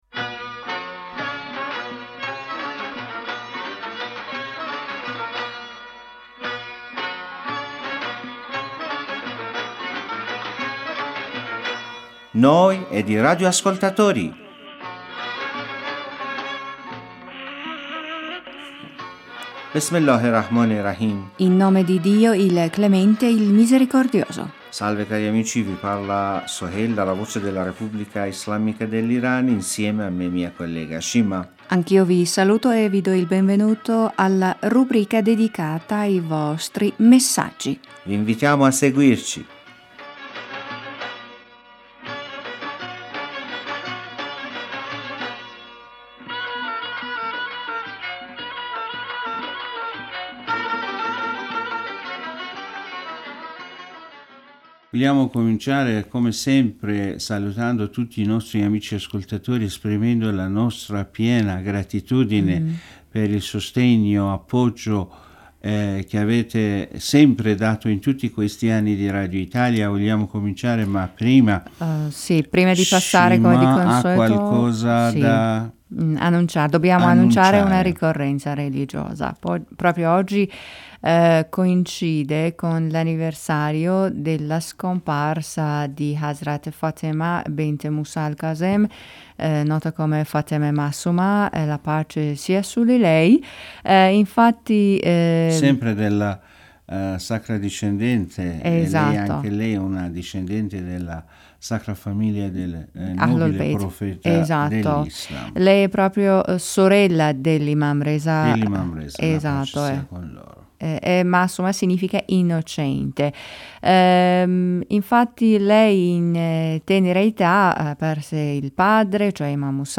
In questo programma in nostri speaker leggeranno i vostri messaggi e commenti.